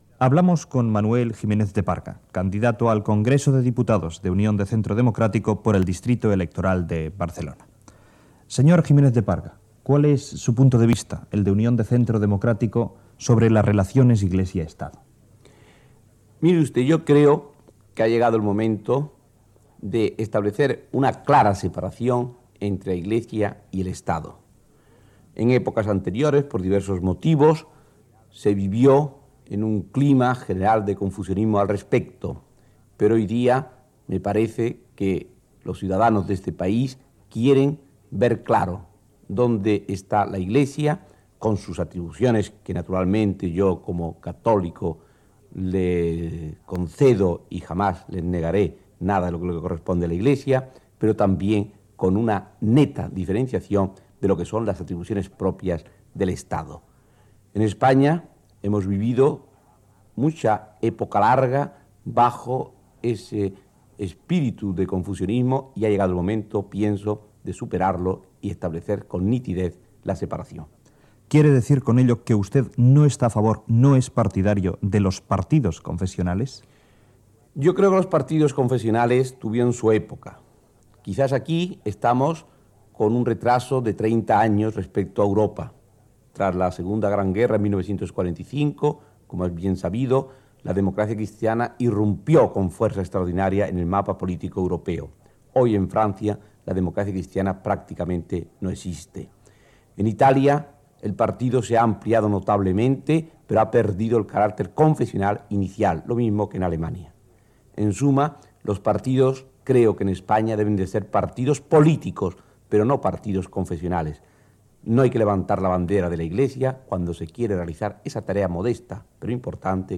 Campanya de Propaganda Política: Unión de Centro Democrático. Amb una entrevista al candidat Manuel Jiménez de Parga